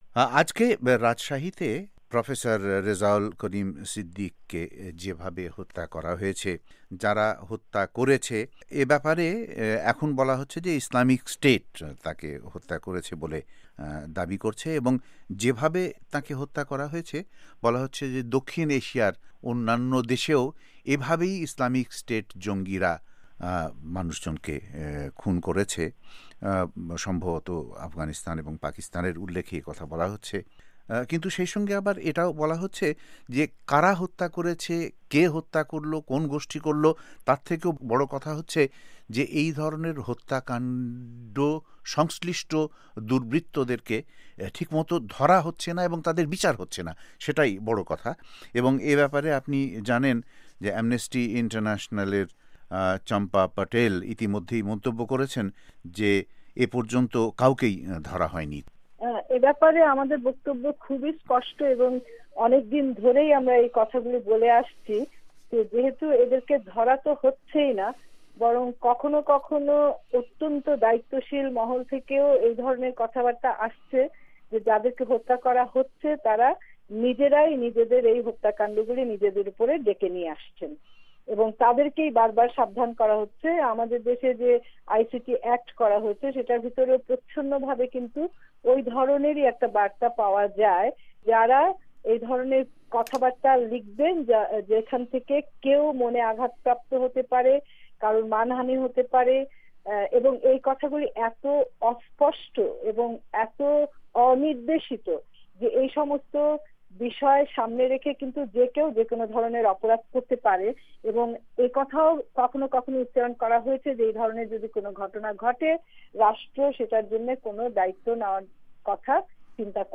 বিষয়টি নিয়ে ভয়েস অফ এ্যামেরিকার বাঙলা বিভাগ কথা বলে মানবাধিকার মতাদর্শী- সুশীল সমাজ প্রবক্তা সুলতানা কামালের সঙ্গে।ভয়েসঅফ এ্যামেরিকার ওয়াশিংটন স্টুডিও থেকে